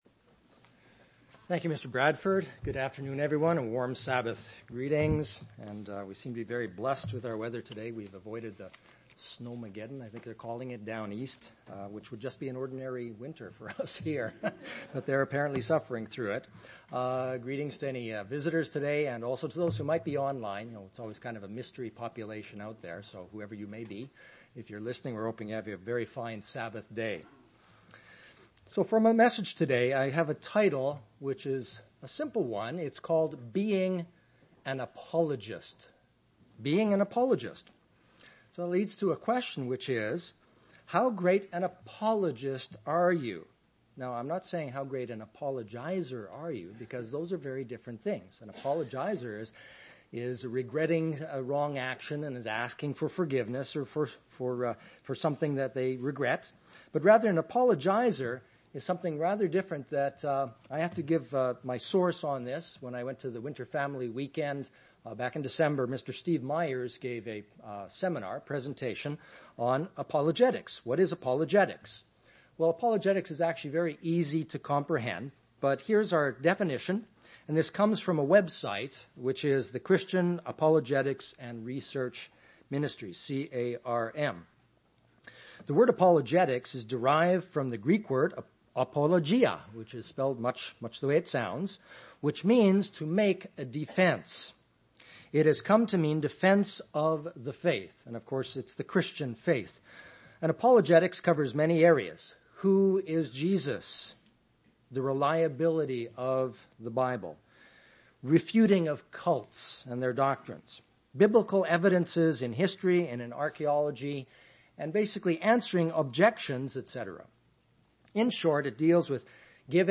Given in Chicago, IL
UCG Sermon Apologetics Studying the bible?